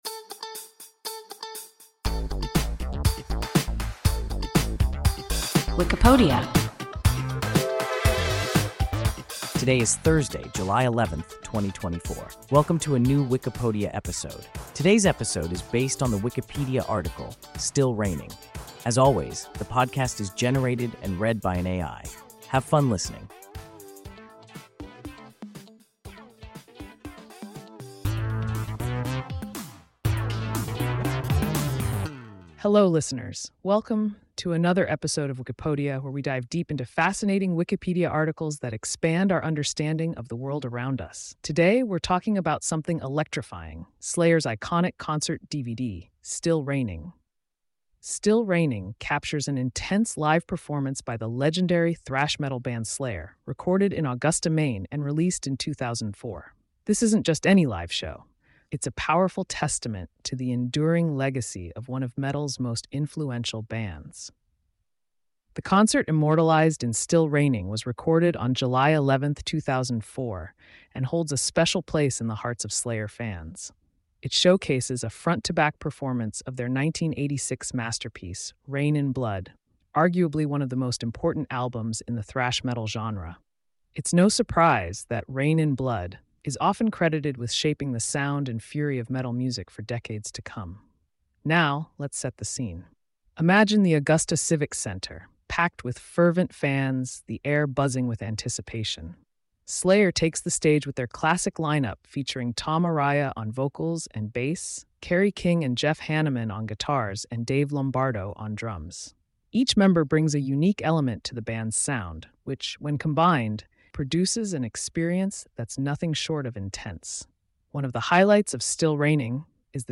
Still Reigning – WIKIPODIA – ein KI Podcast